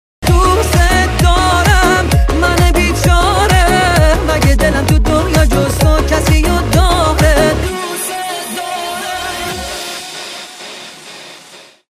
زنگ موبایل
رینگتون پرانرژی و باکلام